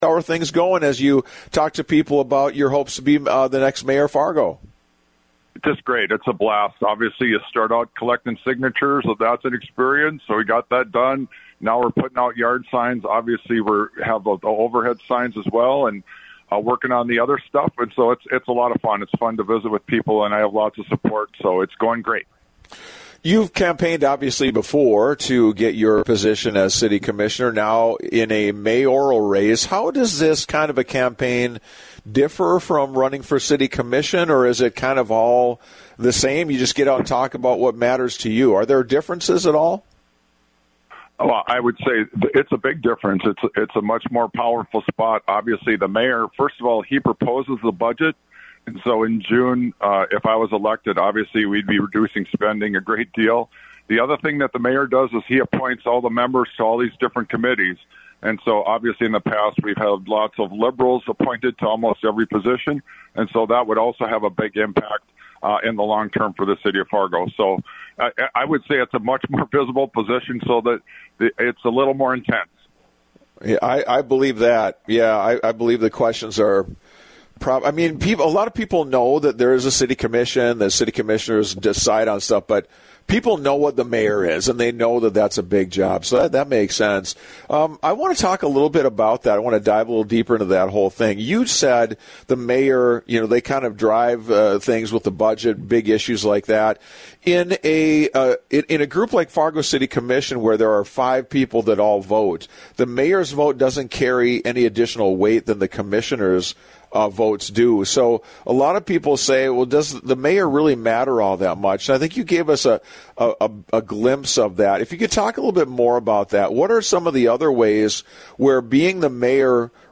LISTEN: Fargo City Commissioner Dave Piepkorn’s conversation